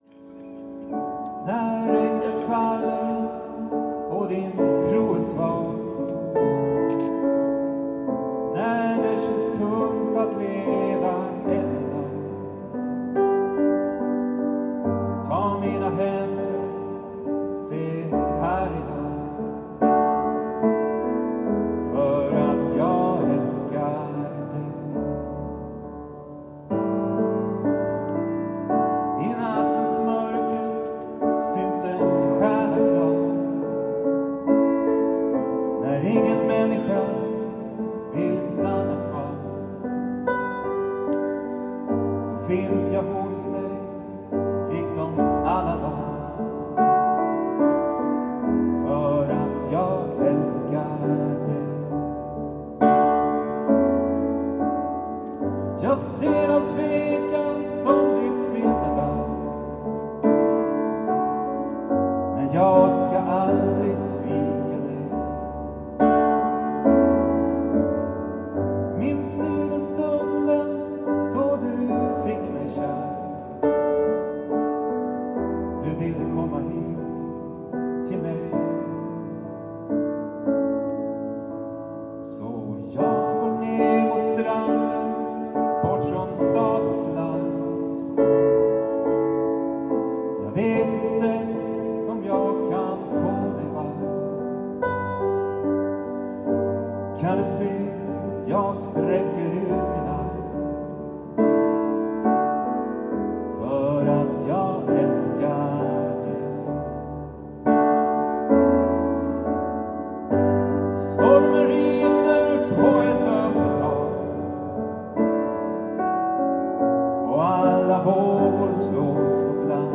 Inspelat i Uppsala domkyrka.
Sånger från Ekumenisk gudstjänst 23 nov 2014.